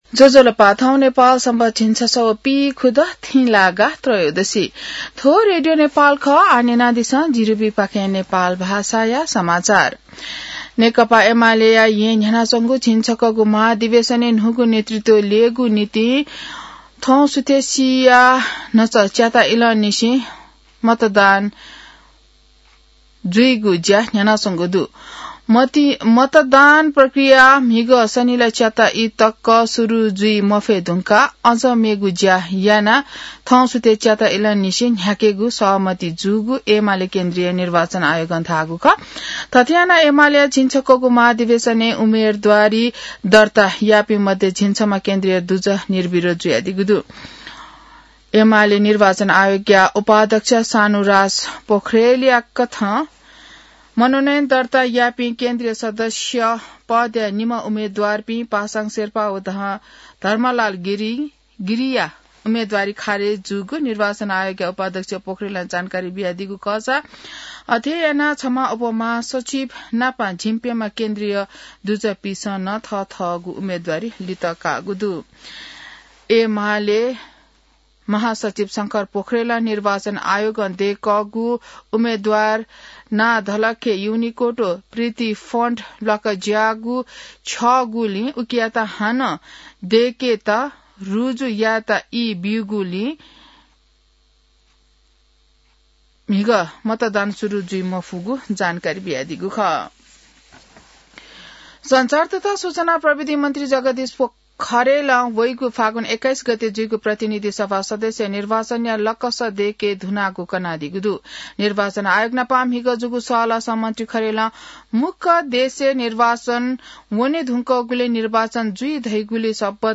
नेपाल भाषामा समाचार : २ पुष , २०८२